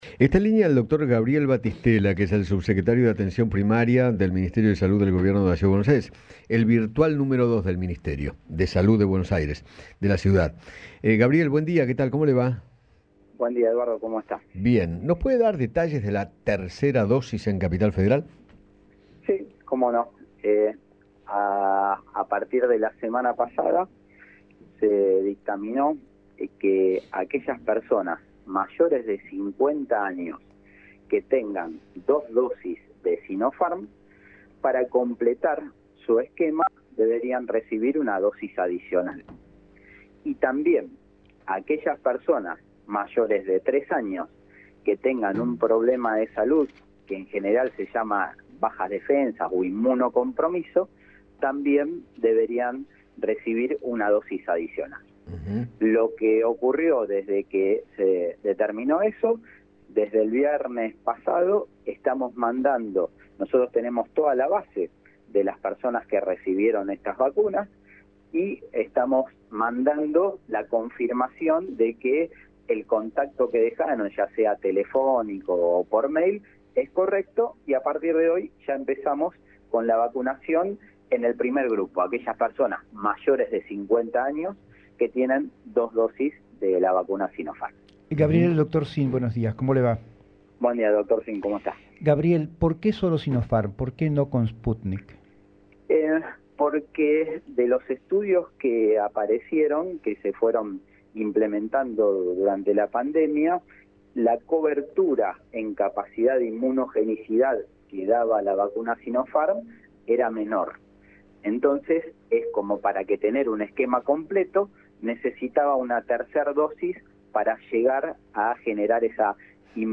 Gabriel Battistella, subsecretario de Atención Primaria porteño, habló con Eduardo Feinmann sobre la aplicación de la tercera dosis en mayores de 50 años con esquema completo de Sinopharm y en los mayores de 3 años inmunocomprometidos.